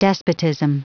Prononciation du mot despotism en anglais (fichier audio)
despotism.wav